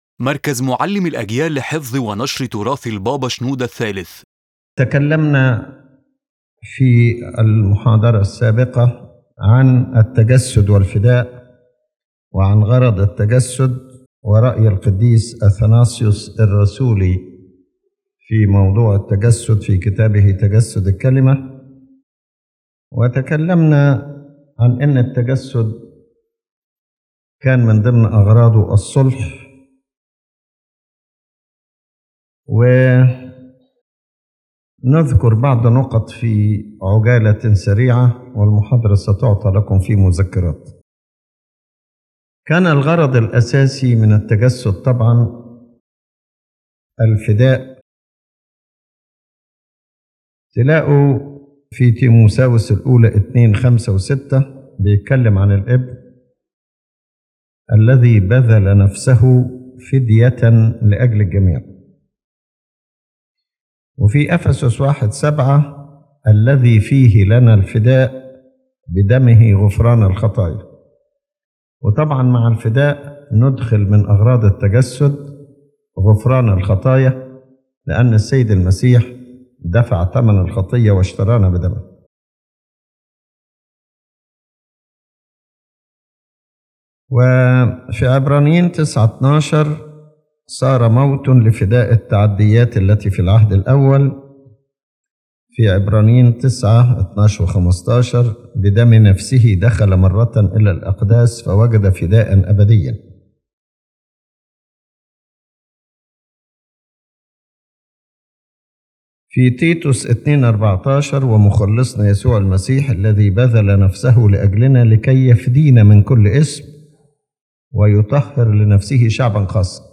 His Holiness explains that the primary purpose of the Incarnation was redemption, as stated in (1 Timothy 2:6) and (Ephesians 1:7): Christ gave Himself as a ransom for all to grant forgiveness of sins.